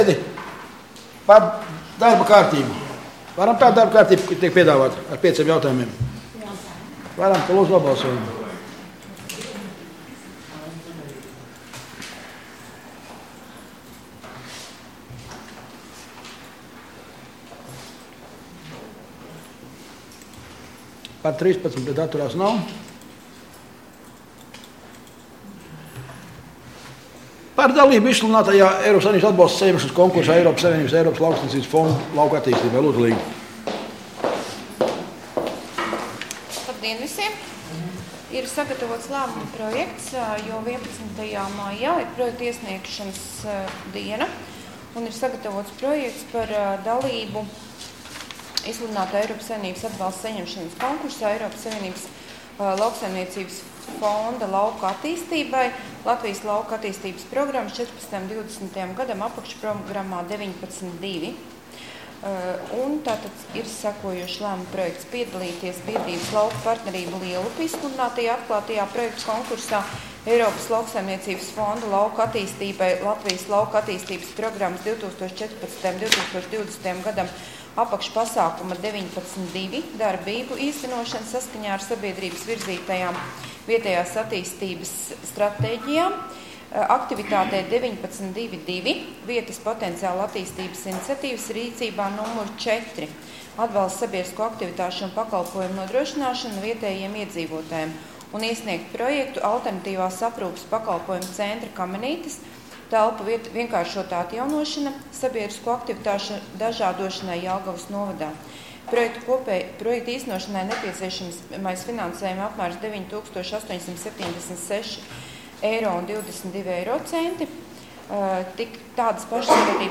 Domes ārkārtas sēde Nr. 6